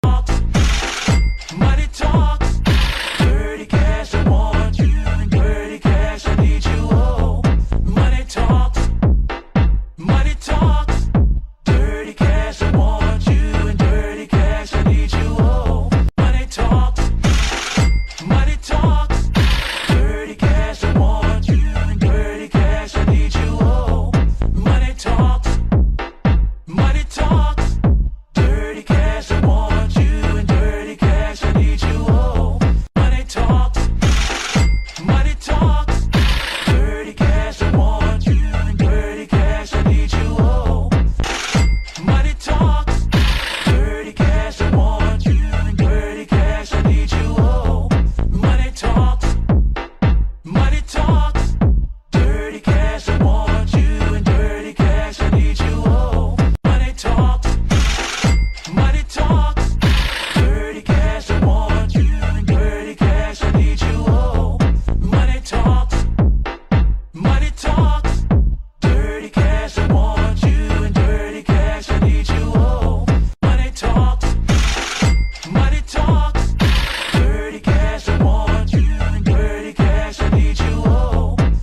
شاد
شاد خارجی